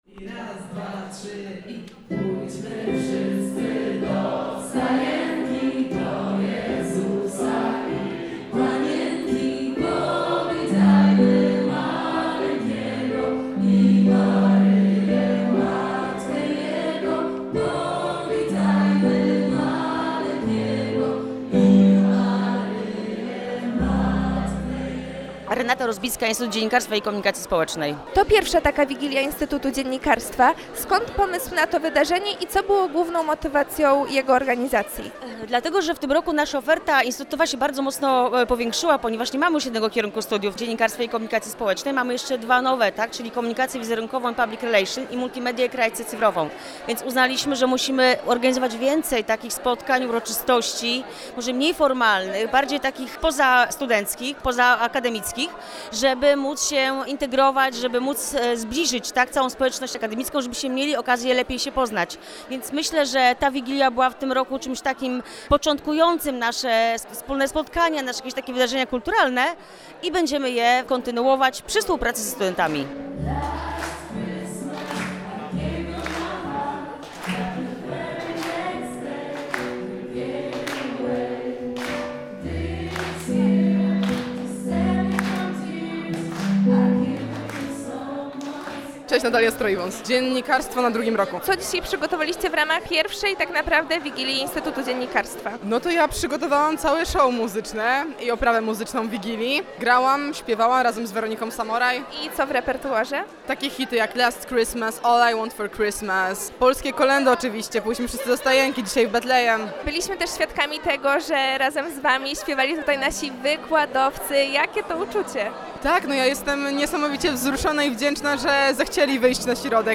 Nie zabrakło oczywiście wspólnego śpiewania kolęd i świątecznych przebojów oraz przysmaków na stole.